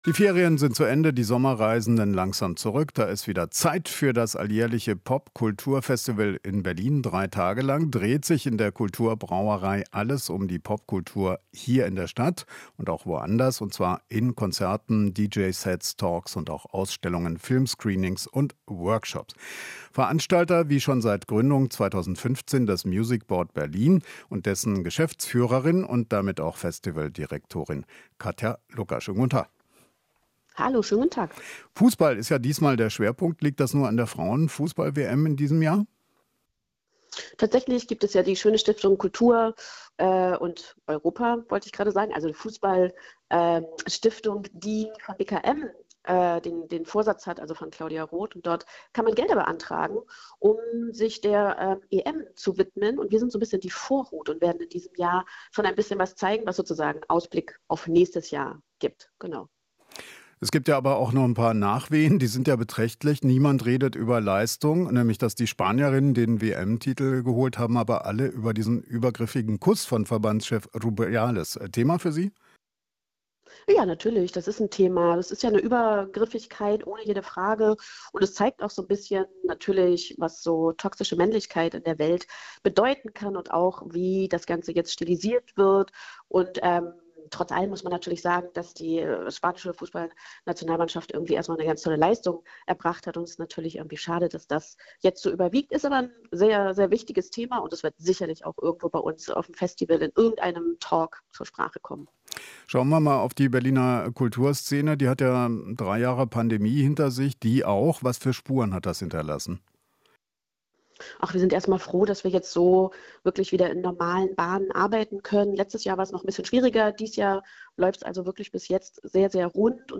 Interview - Festival Pop-Kultur startet in der Kulturbrauerei